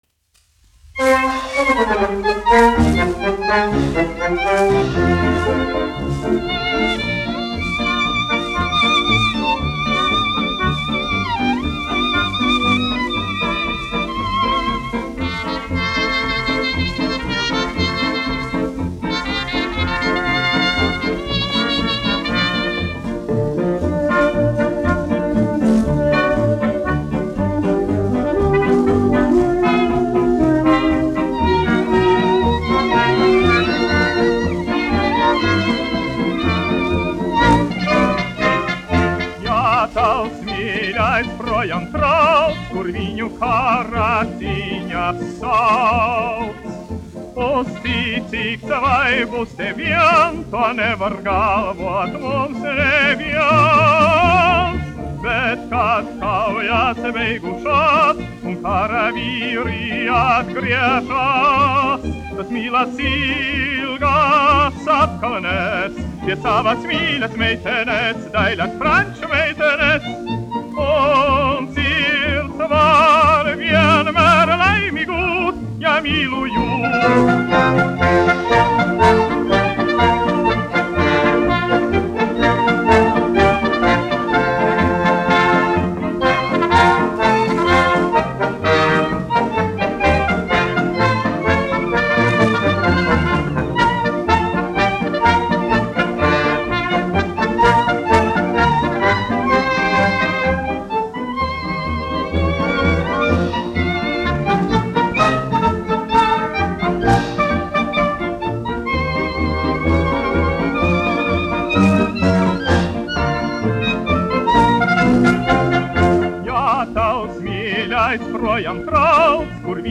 1 skpl. : analogs, 78 apgr/min, mono ; 25 cm
Operetes--Fragmenti
Marši
Latvijas vēsturiskie šellaka skaņuplašu ieraksti (Kolekcija)